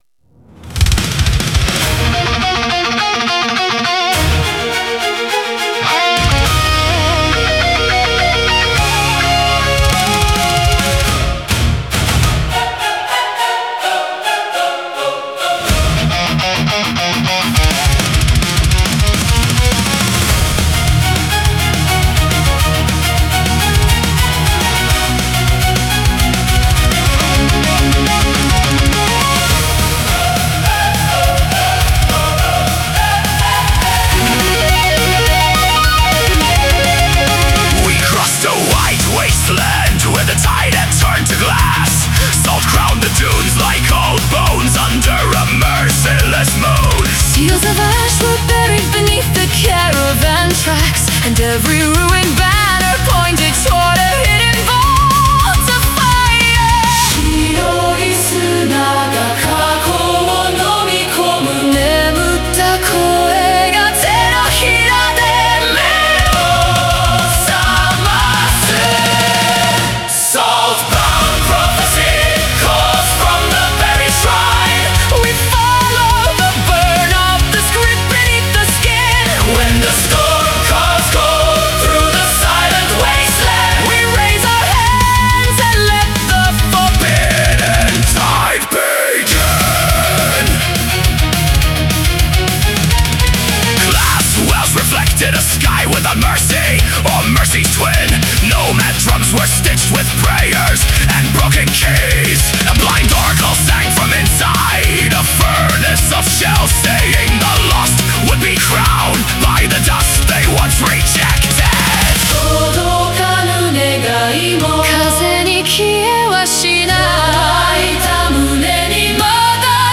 Melodic Power Metal